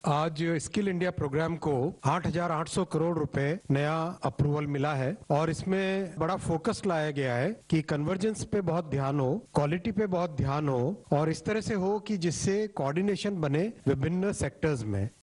ଡେସ୍କ: ସ୍କିଲ୍ ଇଣ୍ଡିଆ କାର୍ଯ୍ୟକ୍ରମ ପାଇଁ କେନ୍ଦ୍ର ସରକାର ୨୦୨୨-୨୩ରୁ ୨୦୨୫-୨୬ ମଧ୍ୟରେ ୮ ହଜାର ୮୦୦ କୋଟି ଟଙ୍କା ମଞ୍ଜୁର କରିଛନ୍ତି। ସୂଚନା ଓ ପ୍ରସାରଣ ମନ୍ତ୍ରୀ ଅଶ୍ୱିନୀ ବୈଷ୍ଣବ ଆଜି ନୂଆଦିଲ୍ଲୀରେ ଗଣମାଧ୍ୟମକୁ ସୂଚନା ଦେଇ କହିଛନ୍ତି ଯେ ଚାହିଦା ଭିତ୍ତିକ ଦକ୍ଷତା ବିକାଶ ଉପରେ ସରକାର ଗୁରୁତ୍ୱ ଦେଉଛନ୍ତି ଏବଂ ସମନ୍ୱୟ ଏବଂ ଗୁଣାତ୍ମକ ଅଭିବୃଦ୍ଧି ଉପରେ ଗୁରୁତ୍ୱ ଦିଆଯିବ।